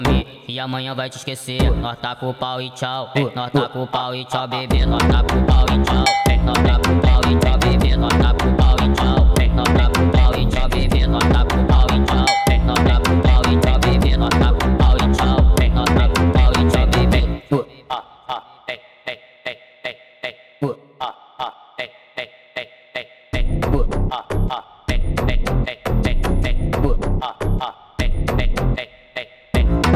Жанр: Иностранный рэп и хип-хоп / Фанк / Рэп и хип-хоп